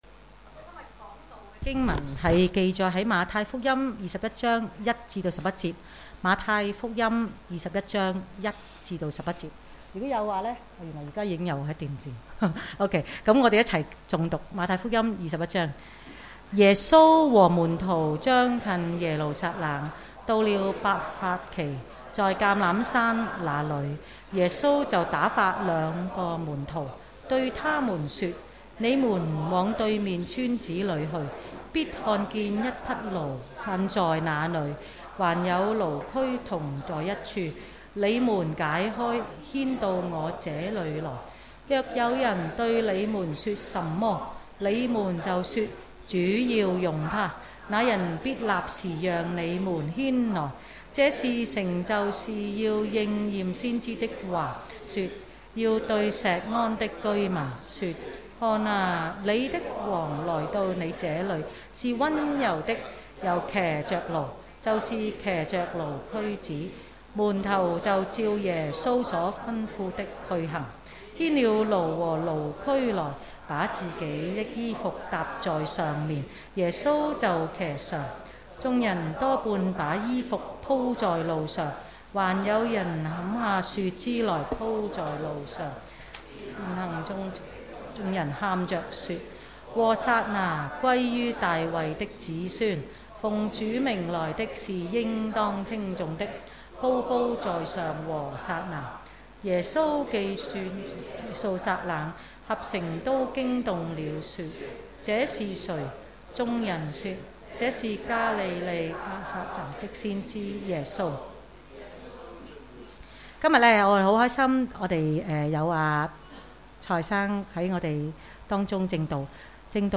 主日崇拜講道 – 主要用牠